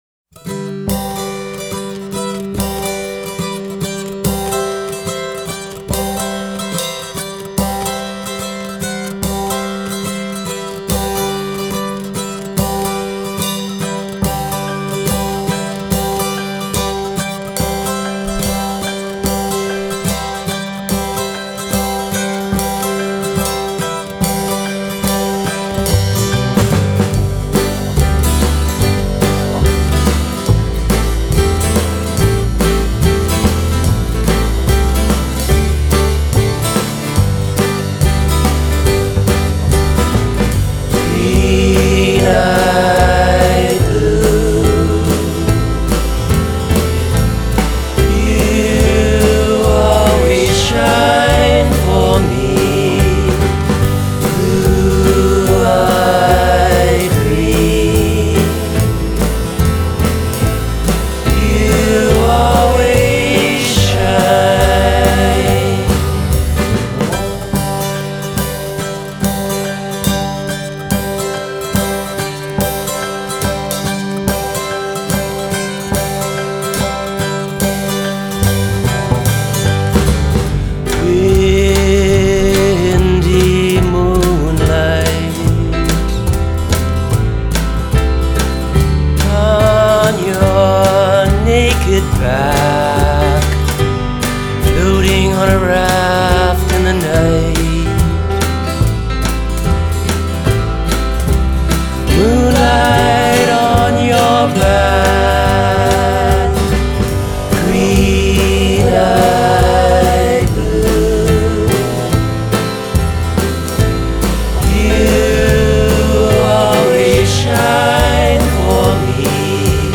vocals/harmonica
vocals/guitars/piano
bass/drums